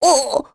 Talisha-Vox_Damage_03.wav